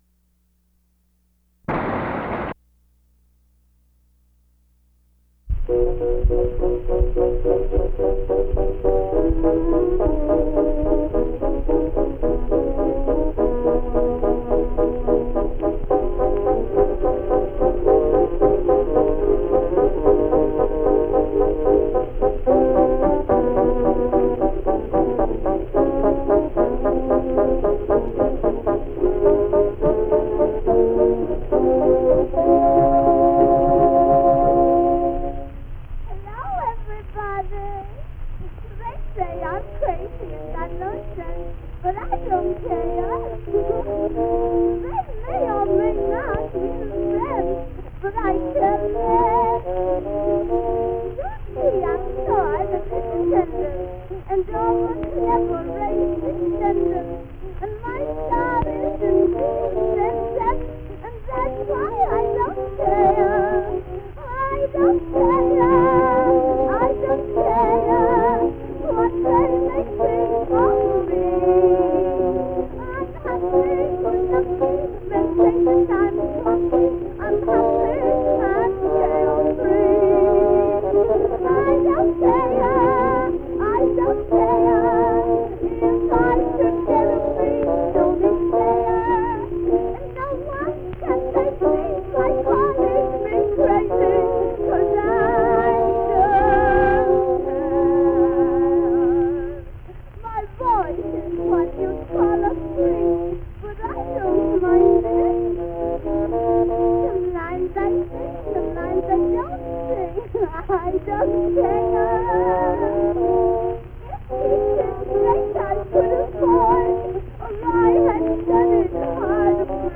Popular music Vaudeville